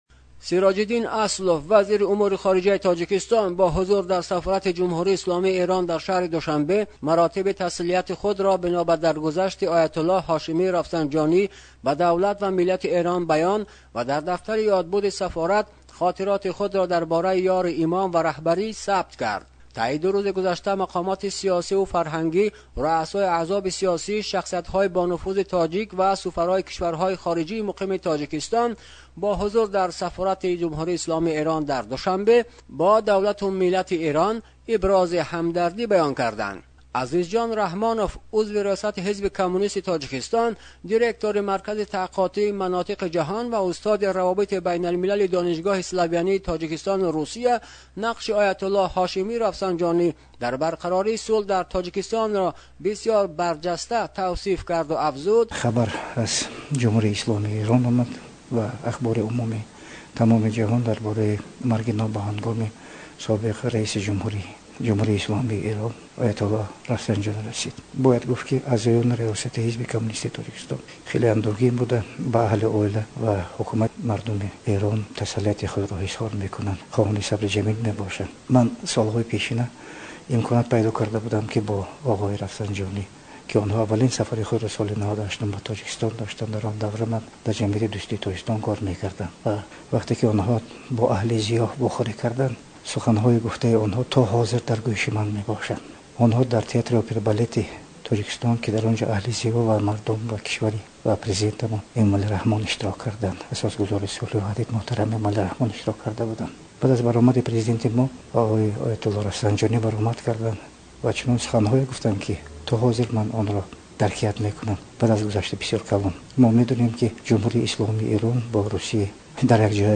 Ахбор / Тоҷикистон